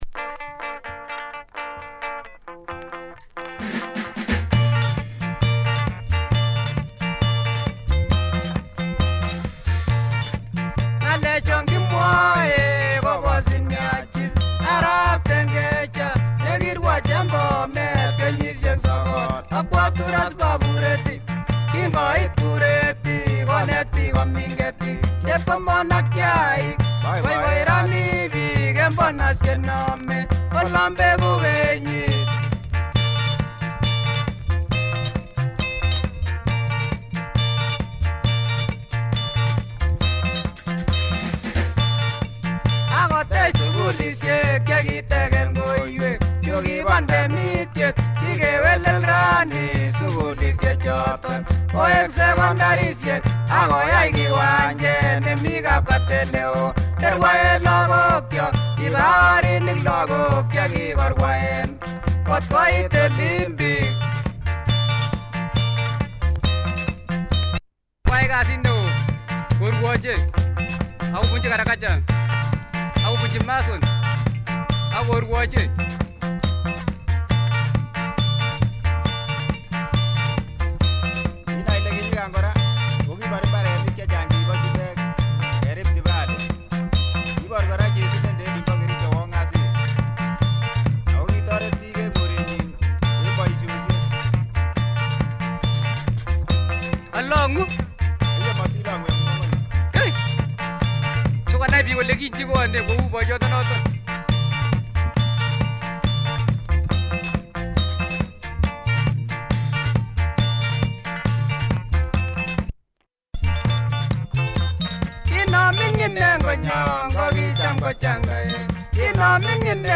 Nice Kipsigis Benga! Tempo and some guitar experimentation.